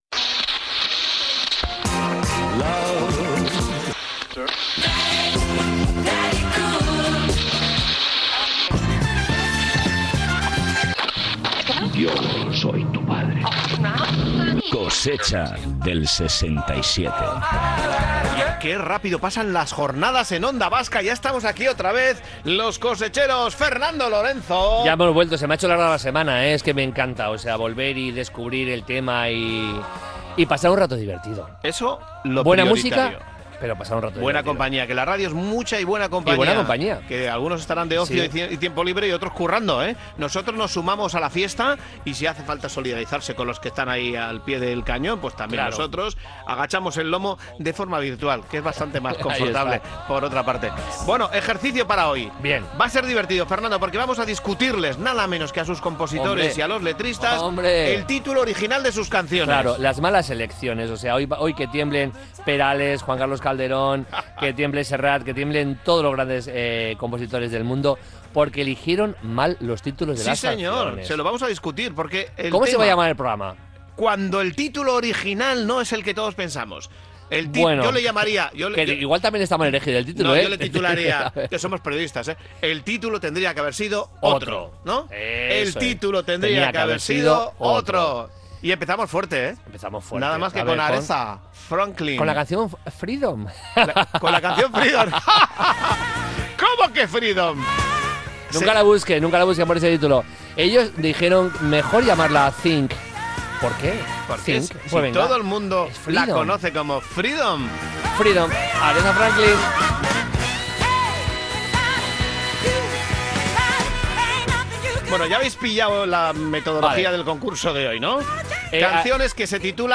Espacio conectado a la nostalgia a través del humor y la música.
Recuerdos sonoros de otro tiempo con la música y el humor como cómplices.